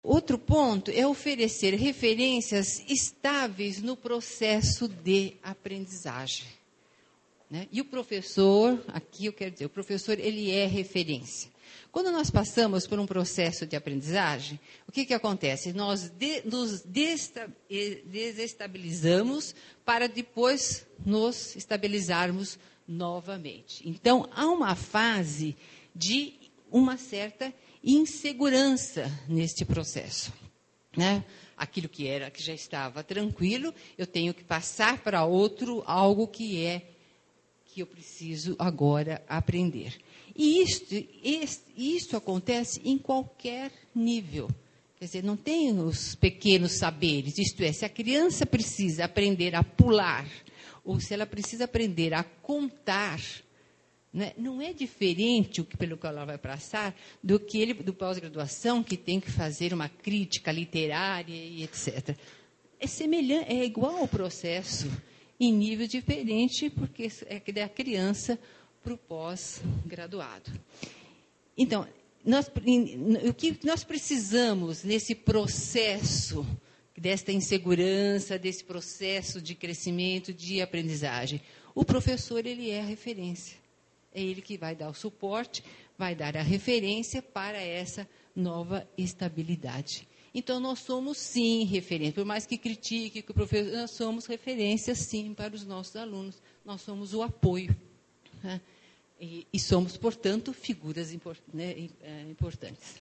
Durante o evento, que contou mais de 400 participantes, falou-se sobre a legislação em vigor, as experiências aplicadas, pesquisas realizadas, sobre os compromissos e responsabilidades da escola e do professor, sobre formação docente.